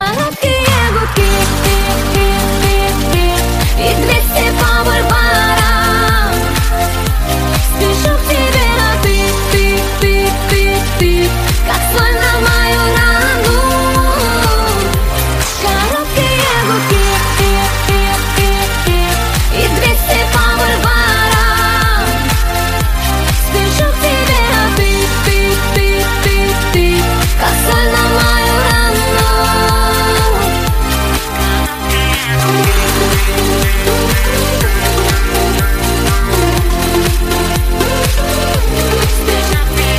громкие